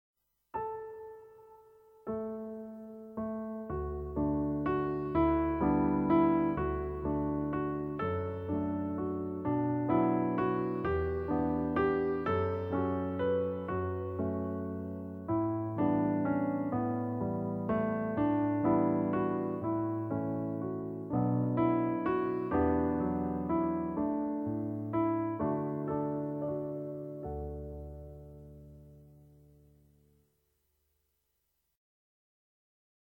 begeleiding